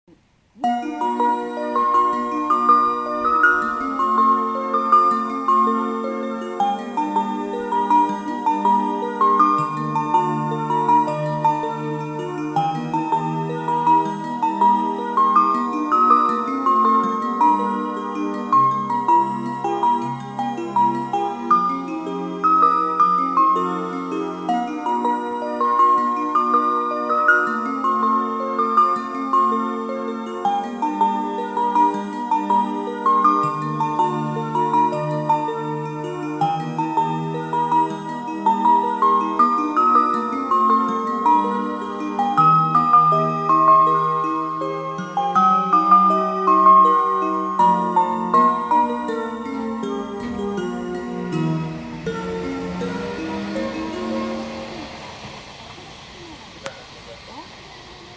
接近メロディーとは、電車がホームに入って来るときに流れる音楽のことです。
さらに、日中は両方向とも同時に到着することが多いため、メロディーが被ってしまうこともあります。